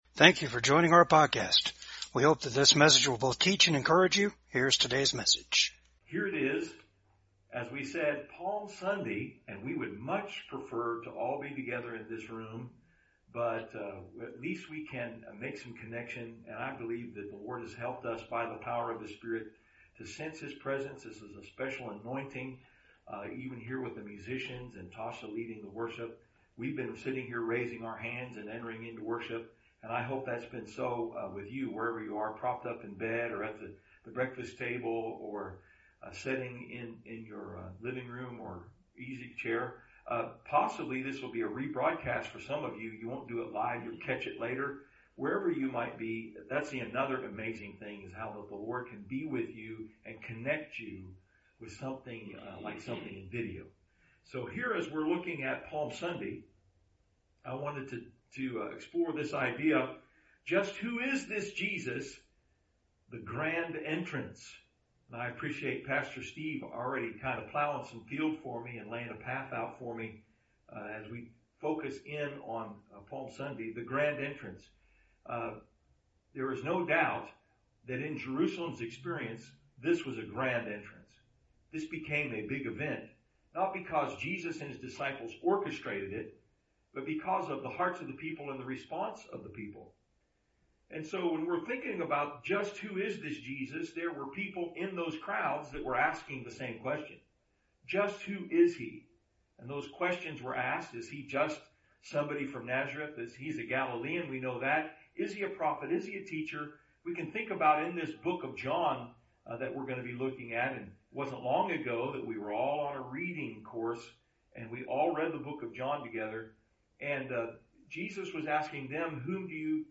Service Type: VCAG SUNDAY SERVICE JESUS IS THE HIGH PRIEST IN THE NEW TEMPLE JESUS IS THE BUILDER, CORNERSTONE, AND CAPSTONE JESUS IS BOTH THE KING AND THE PRIEST IN THE NEW KINGDOM